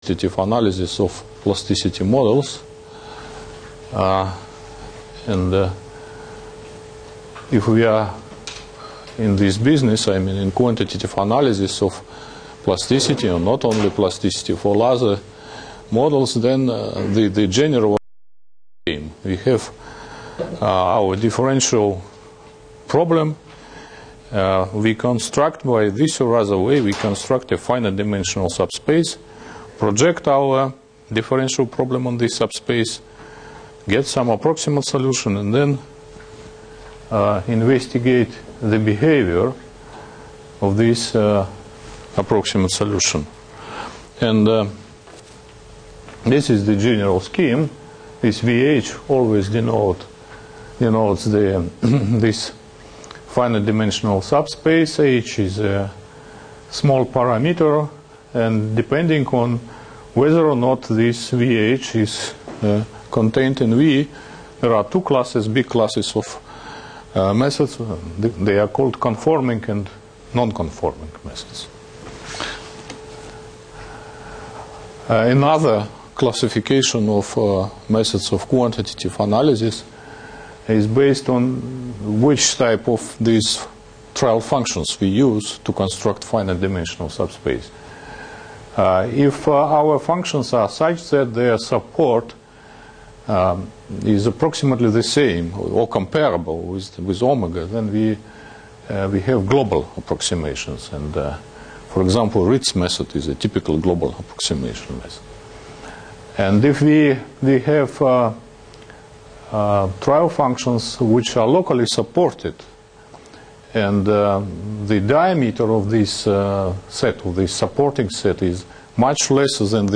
lecture series on mathematical theory of plasticity